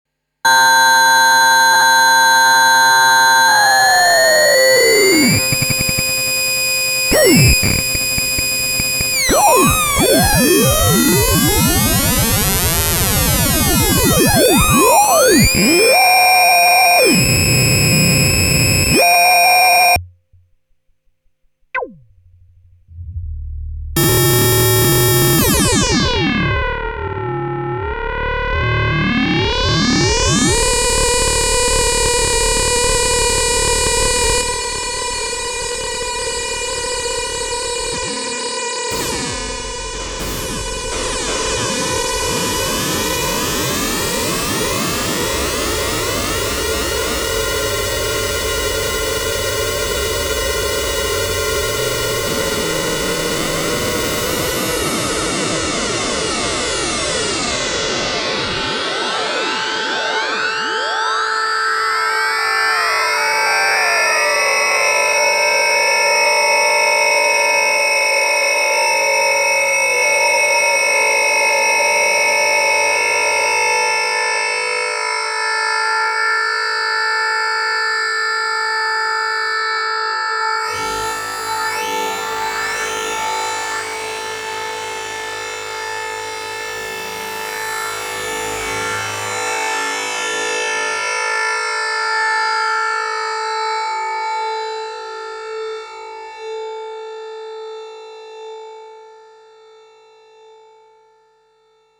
2: Filter FM
Dies sind MODoscs oder LFOs, keine VCOS, die sind frei!
Aber - man braucht schnelle Modulation - ja, es ginge auch mit FM, ich habe im ersten Beispiel Random als Rauschfeinheit reingebaut, im zweiten puristischer - als Verlauf, um es genauer zu analysieren.